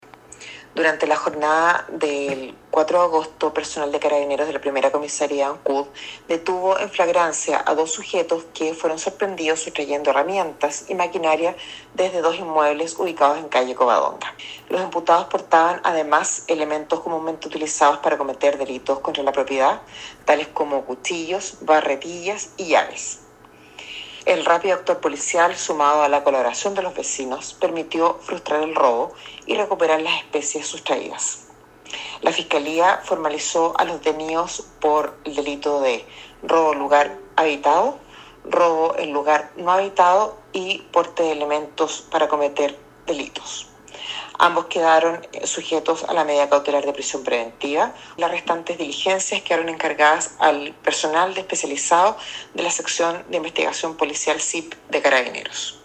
La fiscal (S) Pilar Werner, de la fiscalía local de Ancud, informó que tras la audiencia de formalización ambos quedaron en prisión preventiva.